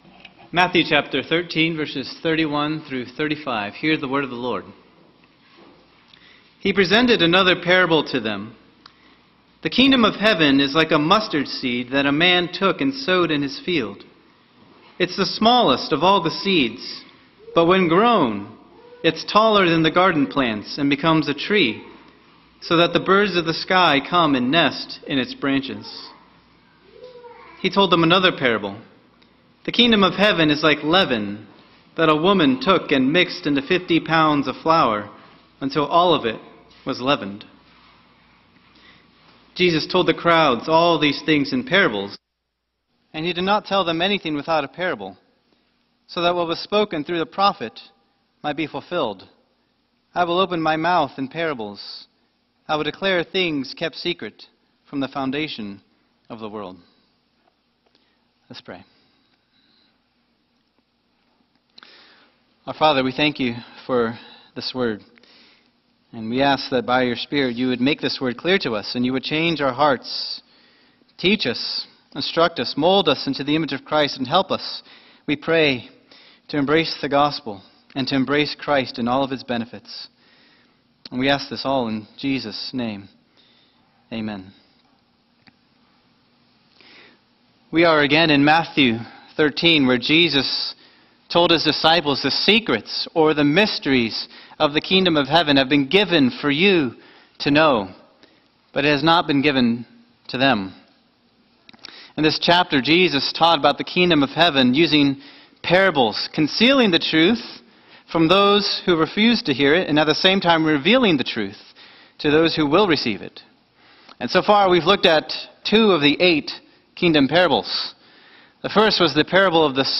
Sermon
2025 at First Baptist Church in Delphi, Indiana.